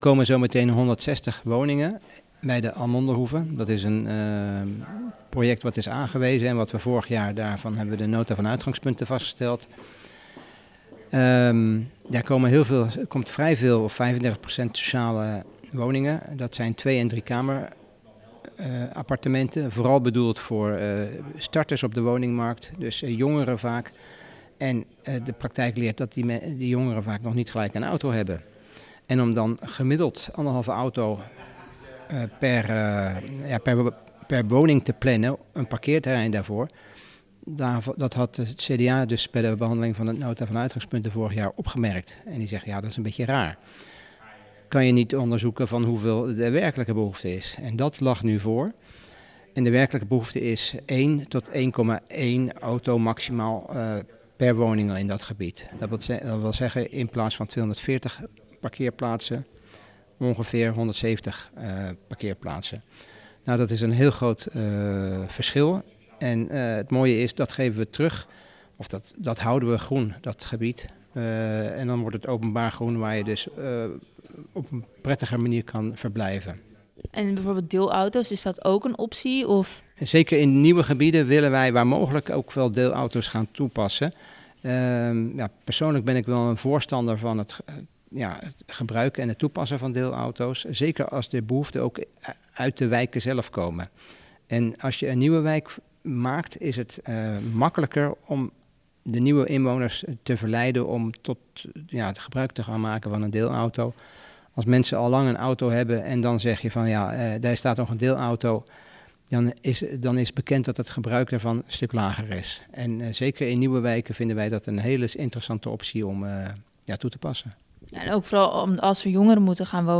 spreekt met wethouder Kees Oudendijk over de pakeerplaatsen voor de Almondehoeve.